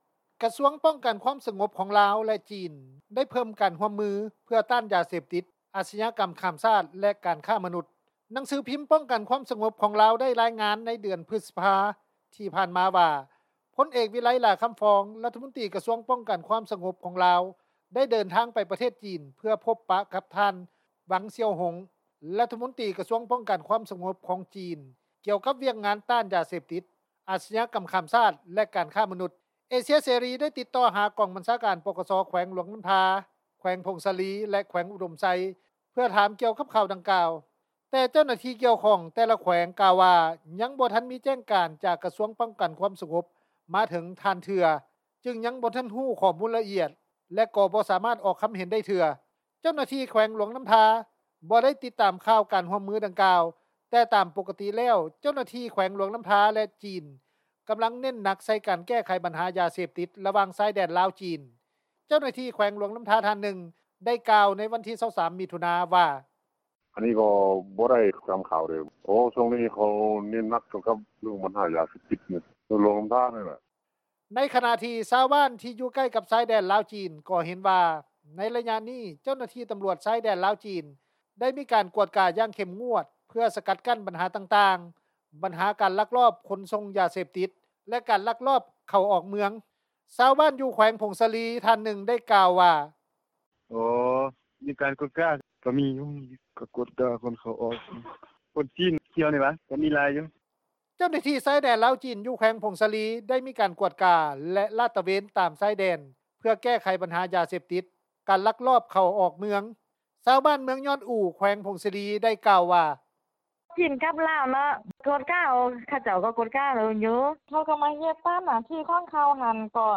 ເຈົ້າໜ້າທີ່ ແຂວງຫລວງນໍ້າທາ ທ່ານນຶ່ງ ໄດ້ກ່າວວ່າ: